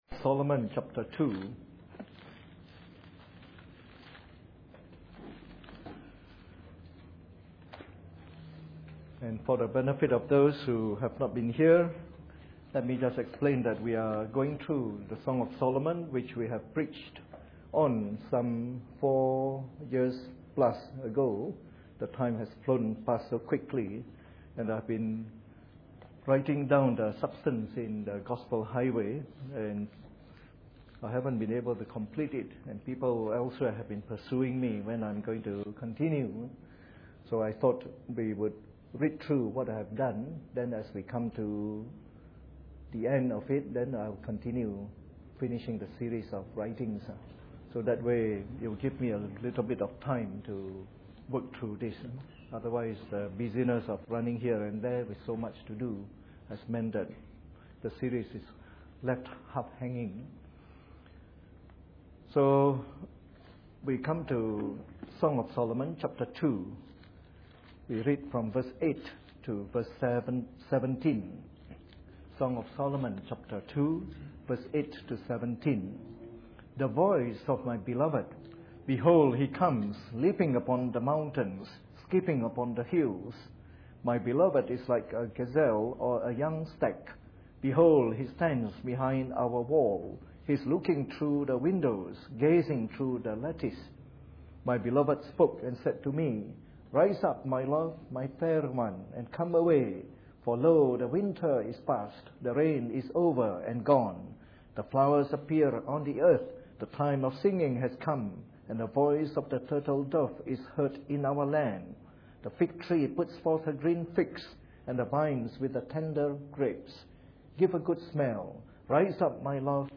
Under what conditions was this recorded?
Preached on the 26th of October 2011 during the Bible Study from our new series on the Song of Solomon.